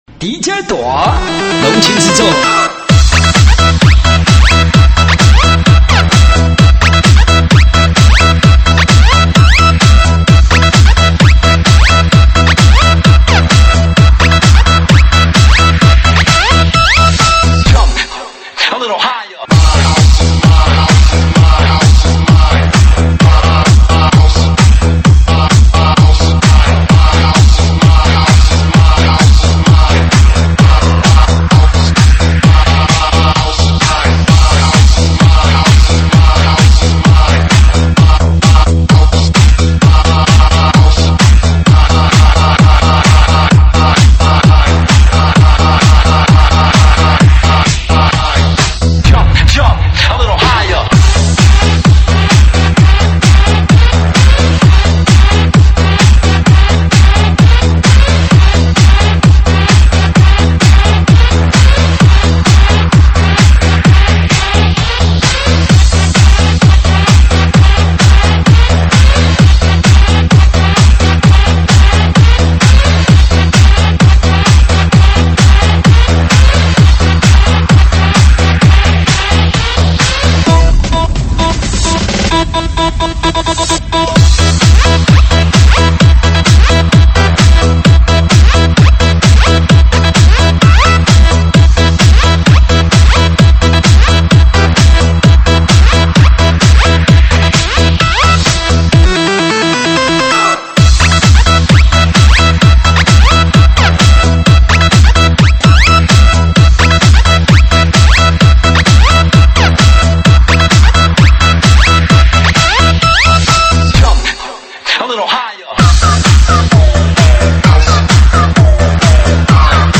栏目： 慢摇舞曲